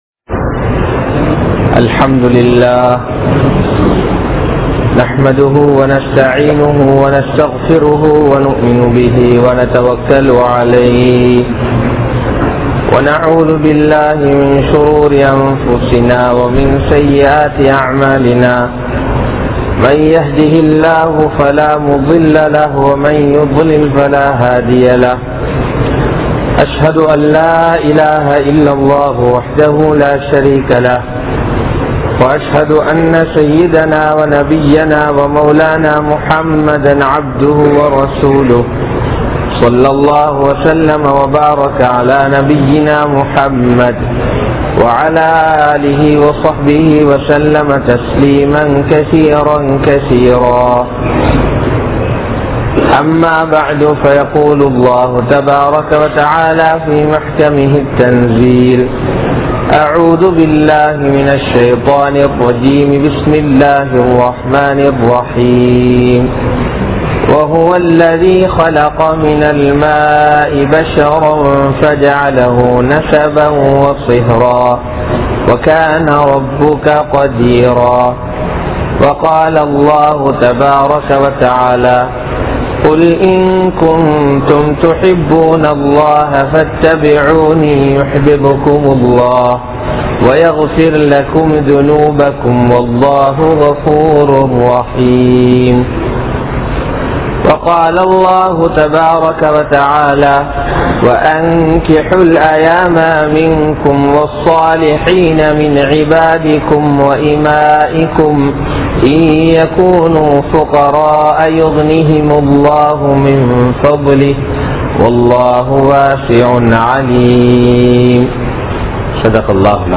Keavalamaana Thirumanagal (கேவலமான திருமணங்கள்) | Audio Bayans | All Ceylon Muslim Youth Community | Addalaichenai
Matara, Muhiyadeen Jumua Masjith